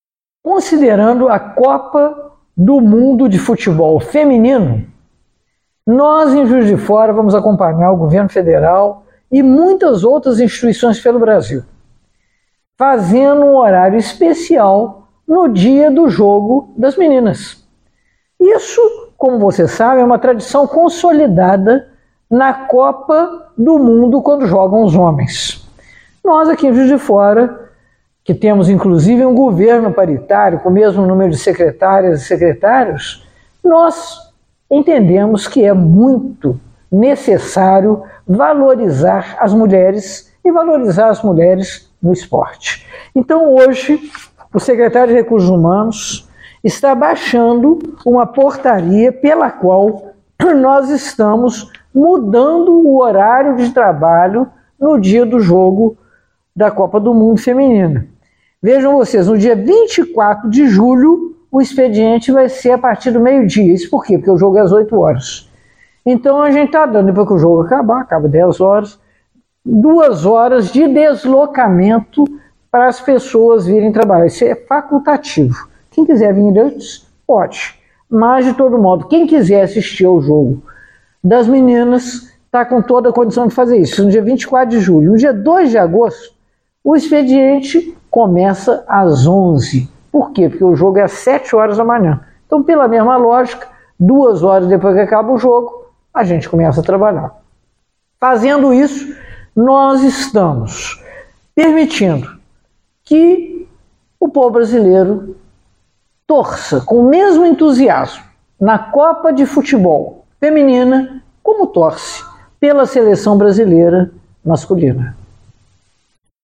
Nas redes sociais, a prefeita de Juiz de Fora, Margarida Salomão, explicou como ficará o início do expediente na administração municipal.